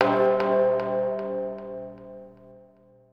Tubular.wav